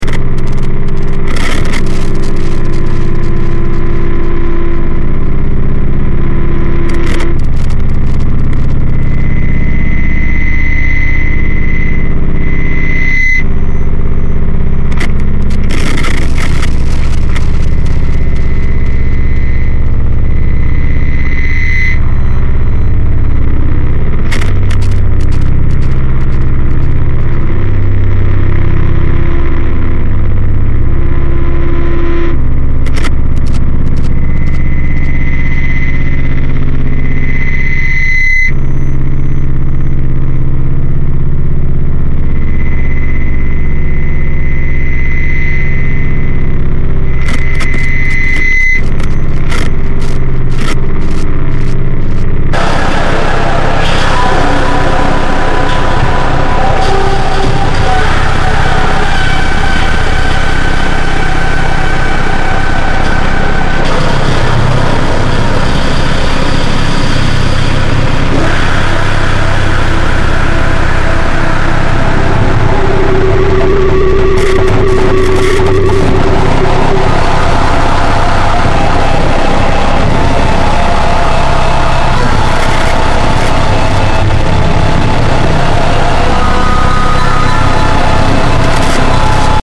brutal masterpiece of wall-noise drone
live recording